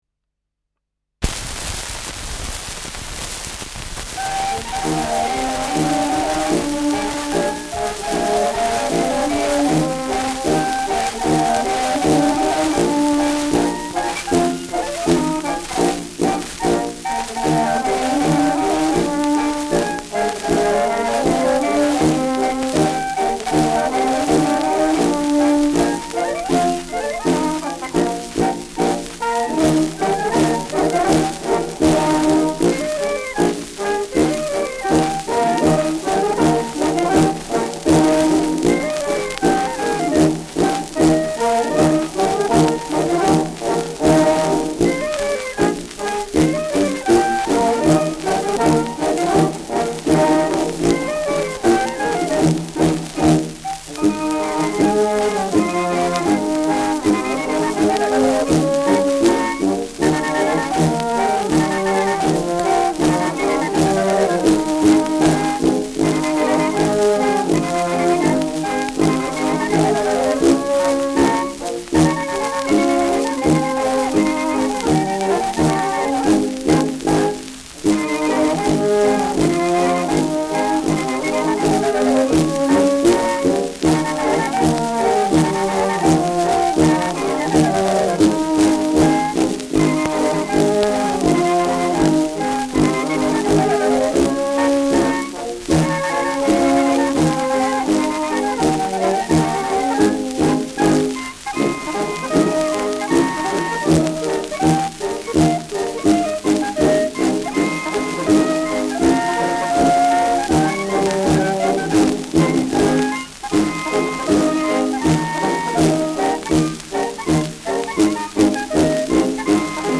Schellackplatte
ausgeprägtes Rauschen